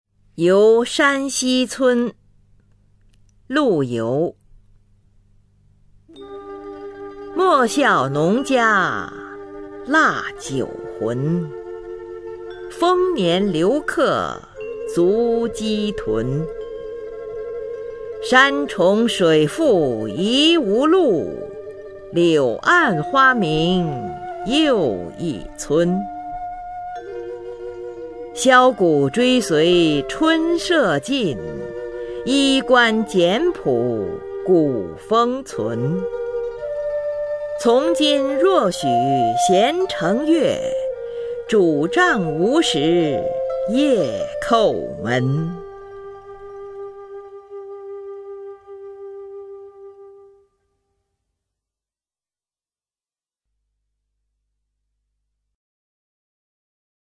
[宋代诗词朗诵]陆游-游山西村（女） 古诗词诵读